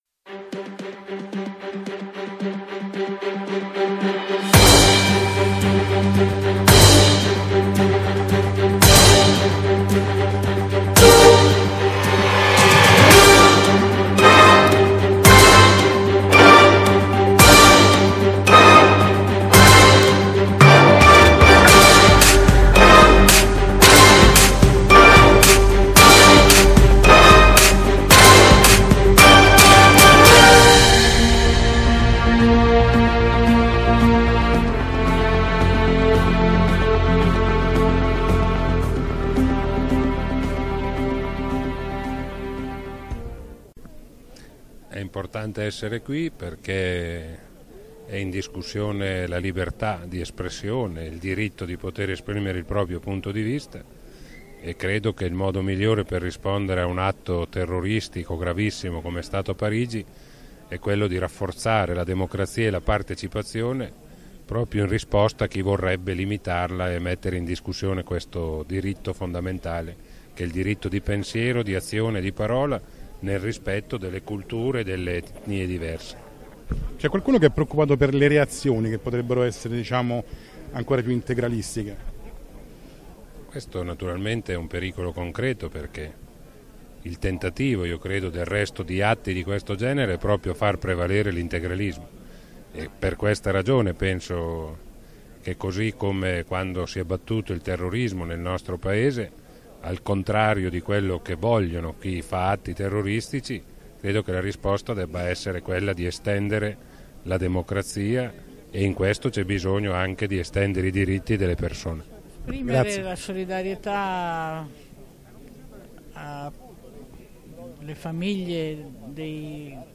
Fiaccolata di solidarietà a seguito dell'assassinio dei giornalisti di Charlie Hebdo uccisi a Parigi il 7/01/2015, Roma piazza Farnese (ambasciata di Francia) 8 gennaio 2015.
Intervistati:
• Maurizio Landini, Segretario FIOM
• Rosi Bindi, Presidente Commissione parlamentare Antimafia (PD)
• Susanna Camusso, Segretaria CGIL
• Maurizio Gasparri, vicepresidente del Senato (FI)